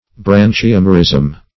Search Result for " branchiomerism" : The Collaborative International Dictionary of English v.0.48: Branchiomerism \Bran`chi*om"er*ism\, n. [Gr.